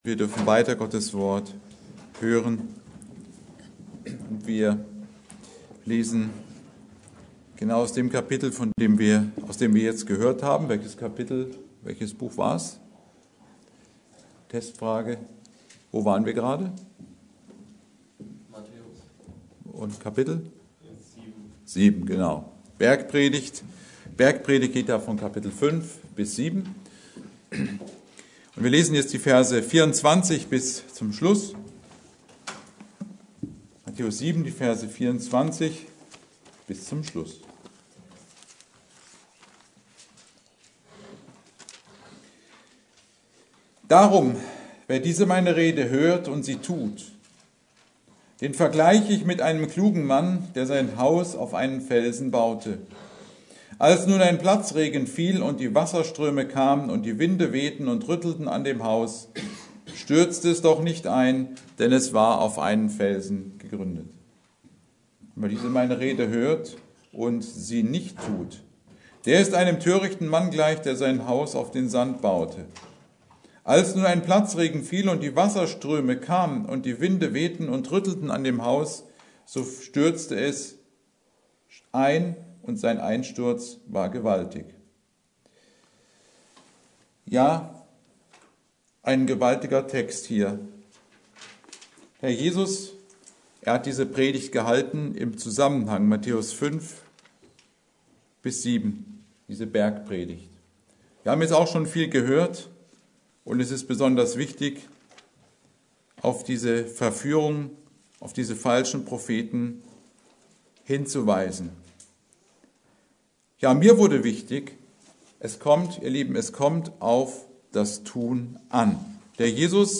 Predigt: Wer diese meine Rede hört und tut
Serie: Gottesdienste Wegbereiter-Missionsgemeinde Passage: Matthäus 7,24-29